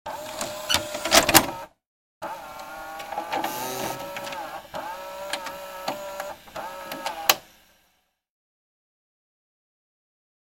На этой странице вы найдете подборку звуков видеомагнитофона: характерное жужжание двигателя, щелчки кнопок, фоновые шумы аналоговой записи.
VHS кассета въезжает в домашний видеомагнитофон